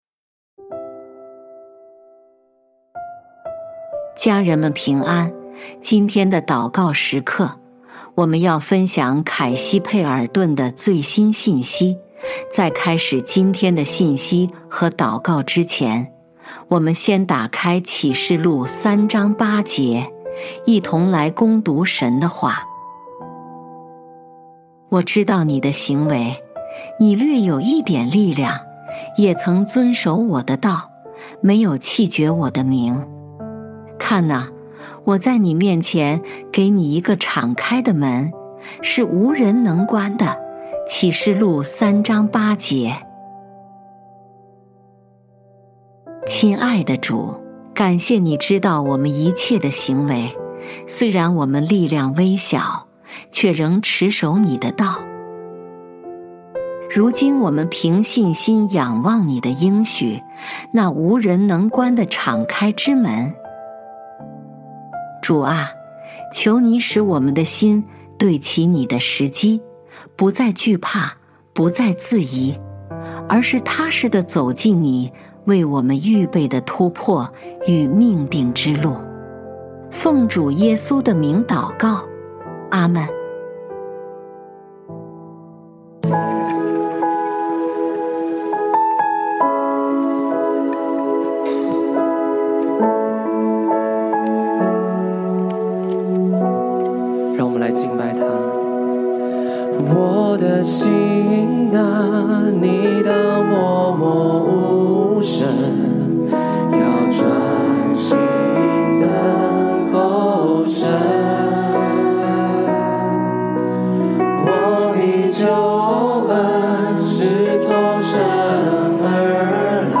本篇是由微牧之歌翻译撰稿祷告及朗读 万事正对齐，门将为你敞开！